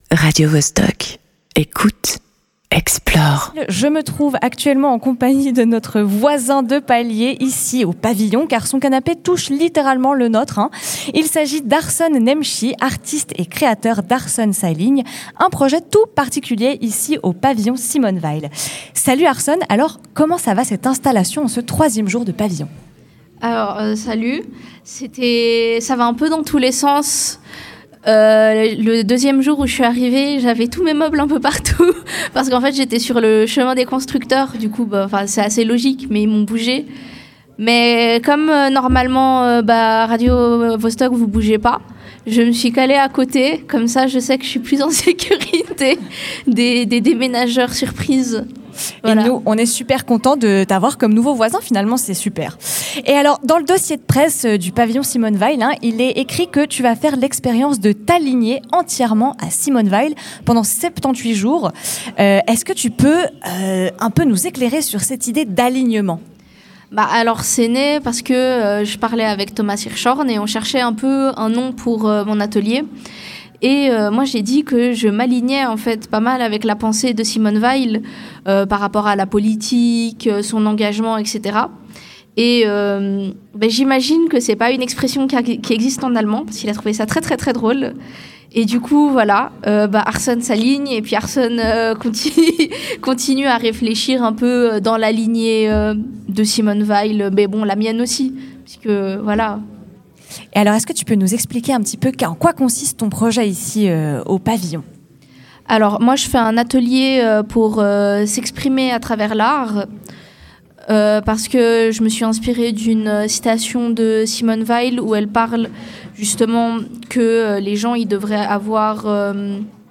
L’interview en apesanteur